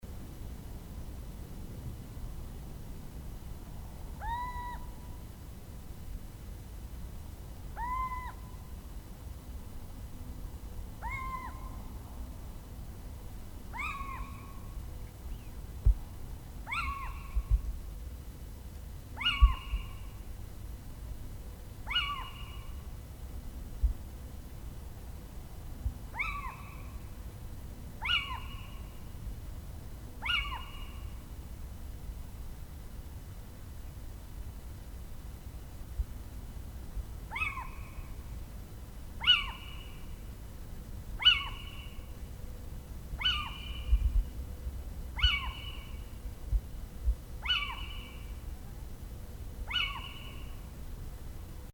Chant-chouette-cheveche.mp3